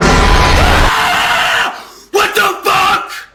Jumpscare Reactions